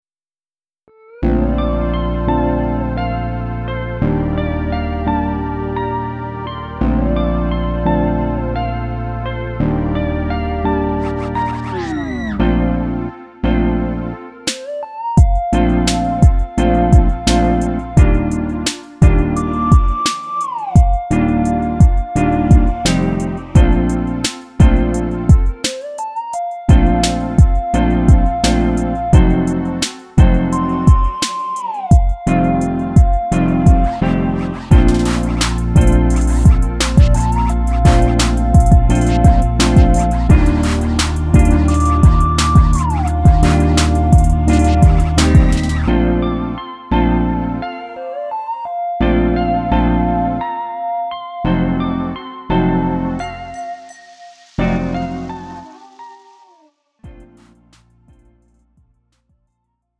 음정 원키 3:14
장르 가요 구분 Lite MR